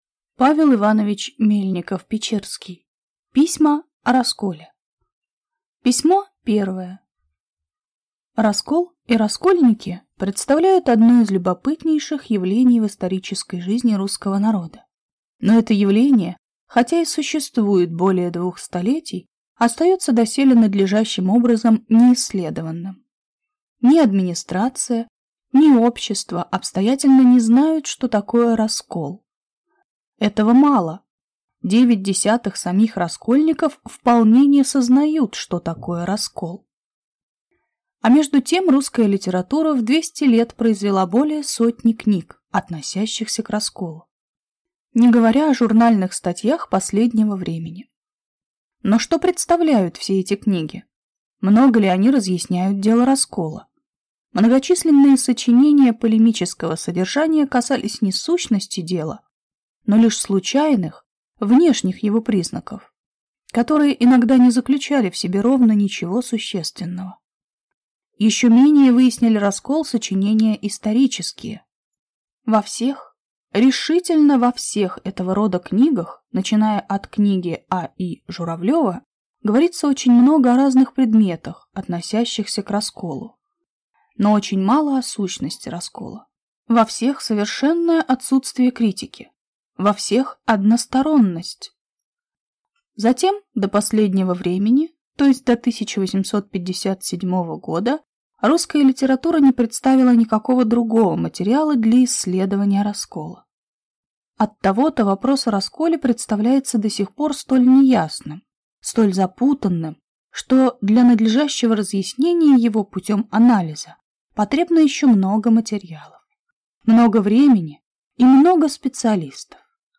Аудиокнига Письма о расколе | Библиотека аудиокниг